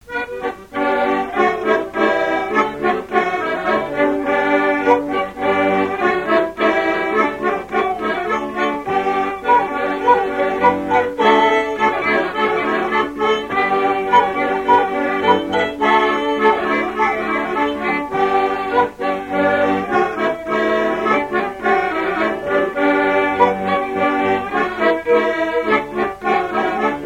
Mémoires et Patrimoines vivants - RaddO est une base de données d'archives iconographiques et sonores.
danse : polka piquée
Pièce musicale inédite